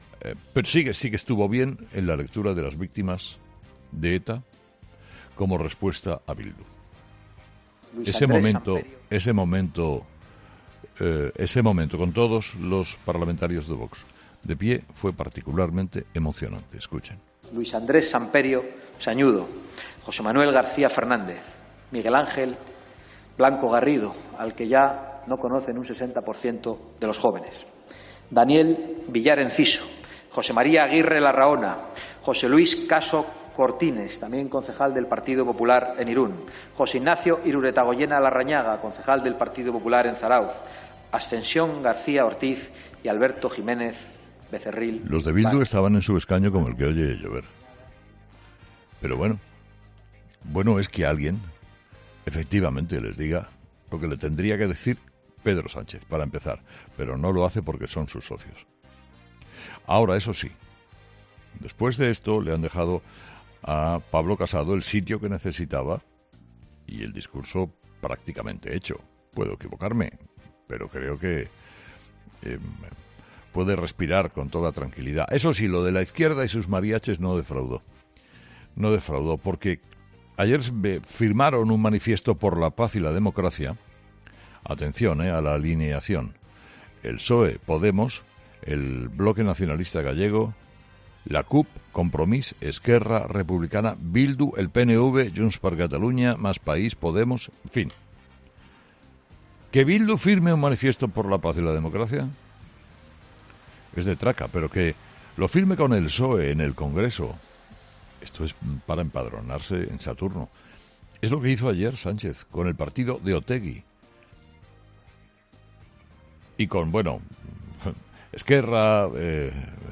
El director de 'Herrera en COPE',Carlos Herrera, ha vuelto a reiterar en los malos datos que estos días estamos conociendo en todas las autonomías por la covid-19.